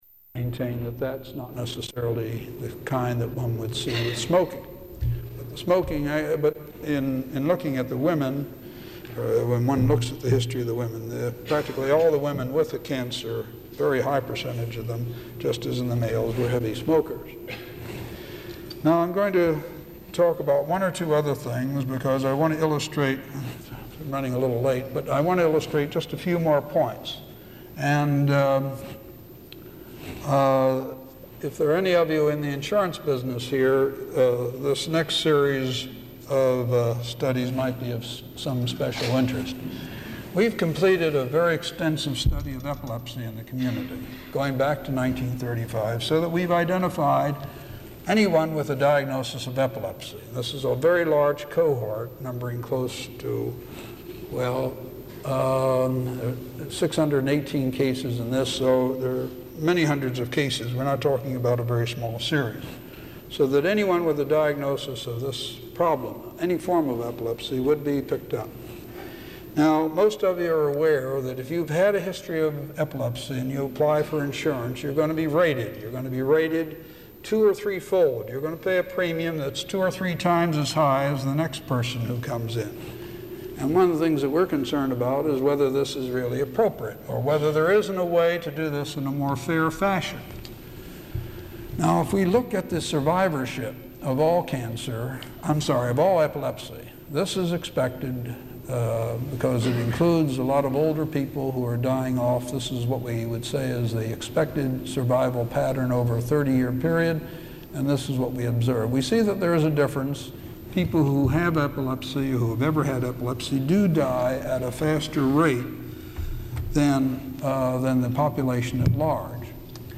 Item consists of a digitized copy of an audio recording of a Vancouver Institute lecture